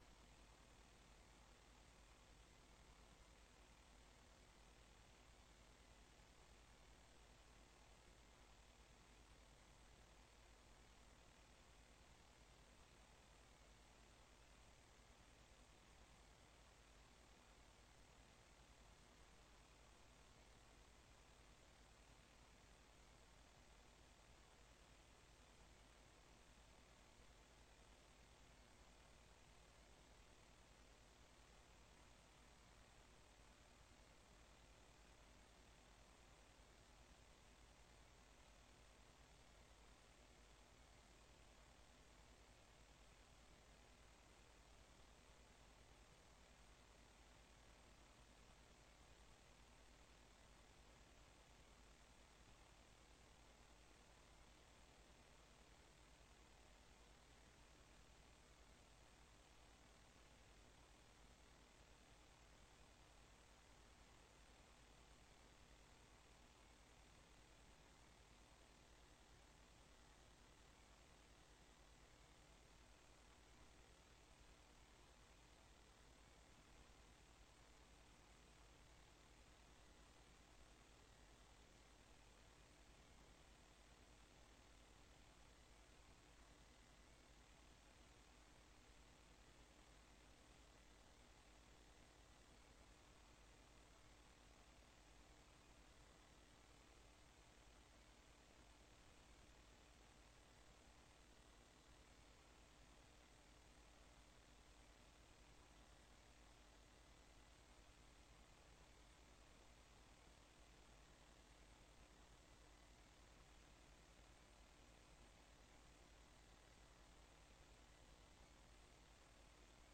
Dit is een opname van een dialoogsessie van de gemeenteraad. In een dialoogsessie worden er gesprekken gevoerd en vragen gesteld zonder politieke kleur.